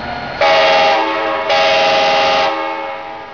Front of 6936 as it departs Union Station, Denver, CO.
WAV (37K) file of horn.
horn.wav